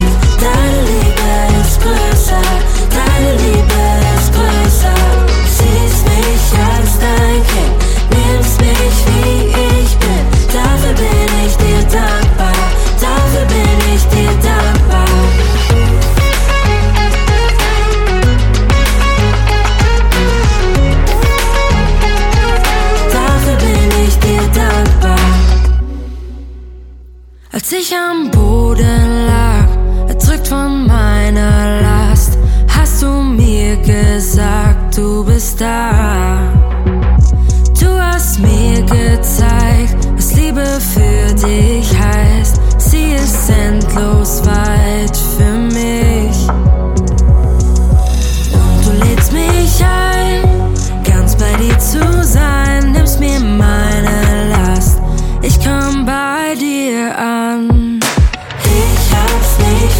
Kraftvoll und intensiv.
Die Songs tragen ein neues Gewand im singbaren Stil.
Gesang.